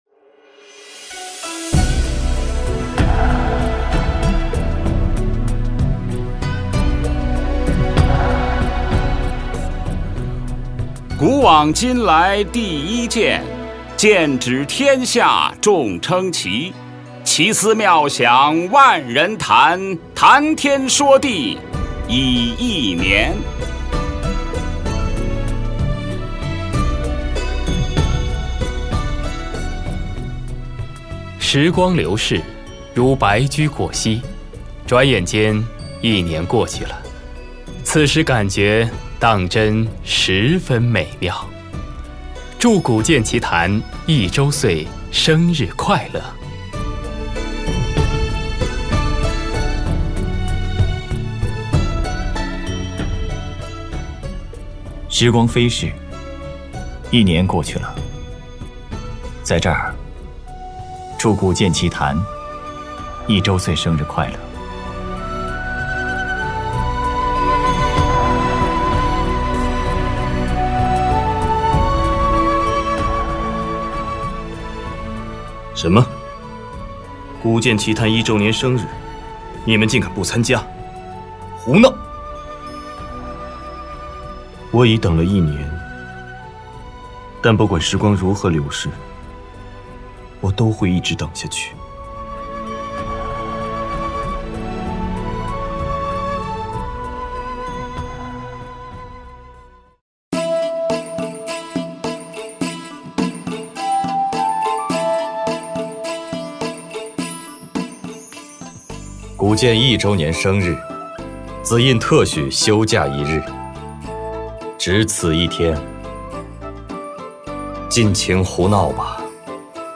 在此，GAMEBAR全体同仁对于各位百忙之中抽出时间赶制音频的配音老师们的支持和贡献，表示由衷的感谢。
>>点击下载配音老师贺《古剑奇谭》周年MP3<<